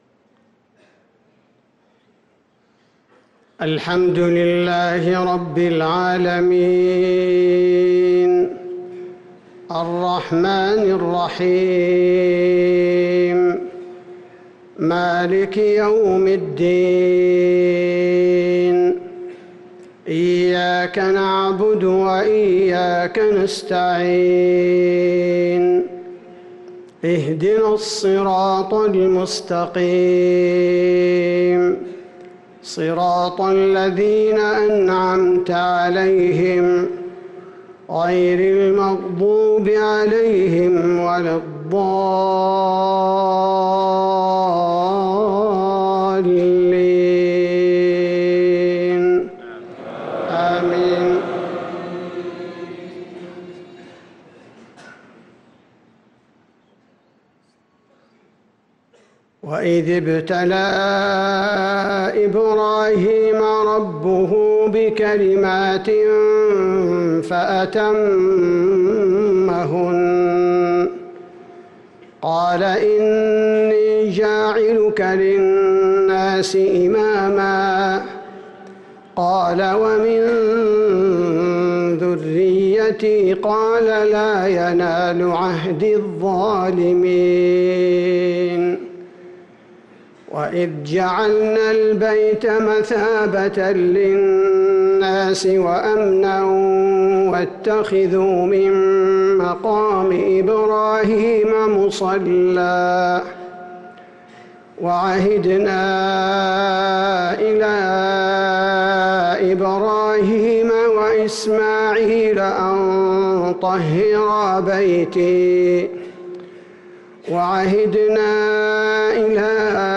صلاة المغرب للقارئ عبدالباري الثبيتي 7 محرم 1445 هـ
تِلَاوَات الْحَرَمَيْن .